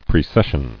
[pre·ces·sion]